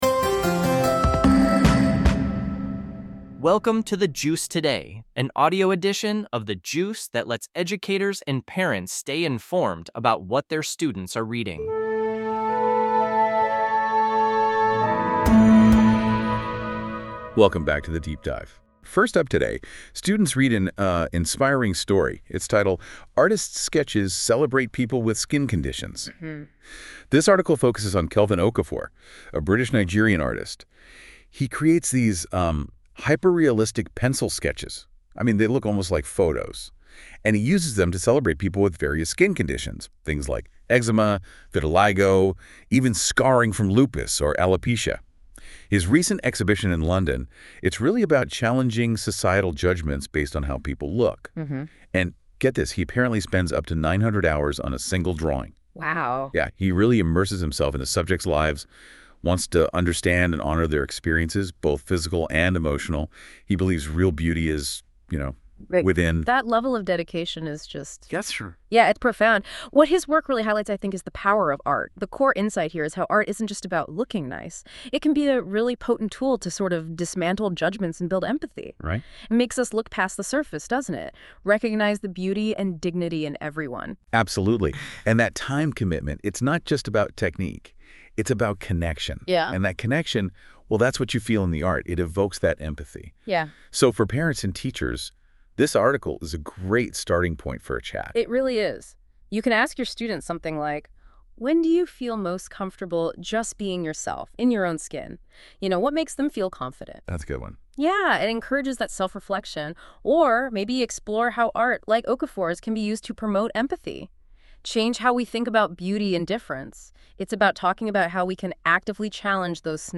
In today's edition: Skin Art Desperate Texas Search Hot Dog Global Millionaire Surge Budget Signed Visit Us Online The Juice Learning (Website) Production Notes This podcast is produced by AI based on the content of a specific episode of The Juice.